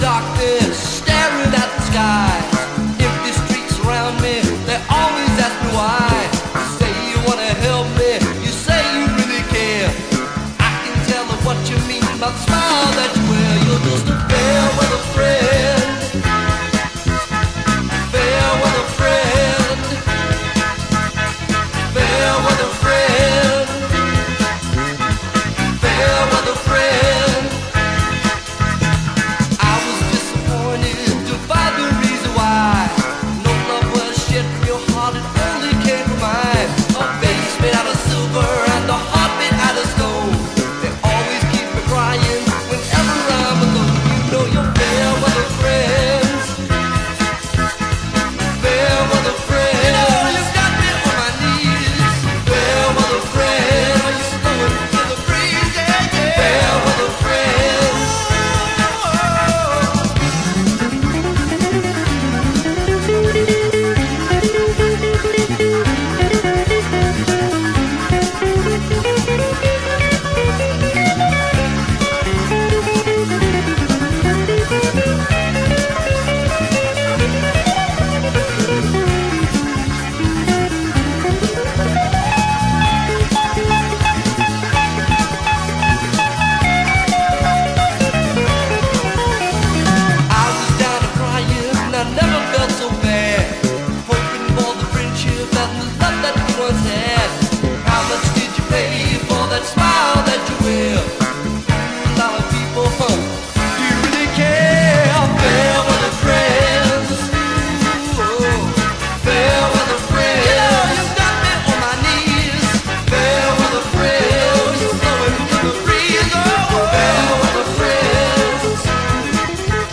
Cut loud.
Disco / soul / funk reissue